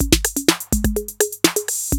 TR-808 LOOP1 1.wav